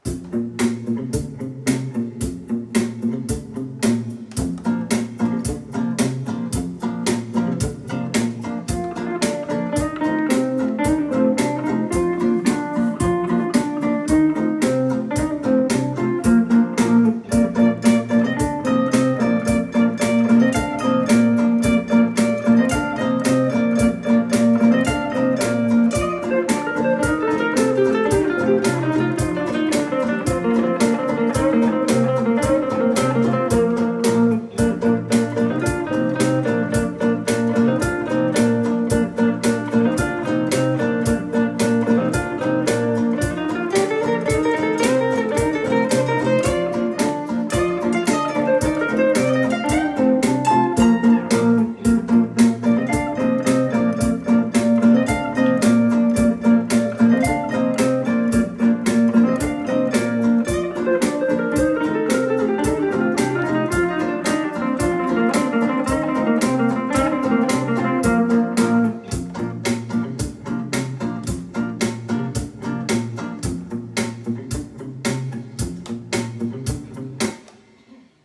Aire culturelle : Couserans
Lieu : Sainte-Radegonde
Genre : morceau instrumental
Instrument de musique : guitare électrique ; batterie ; guitare basse électrique
Danse : polka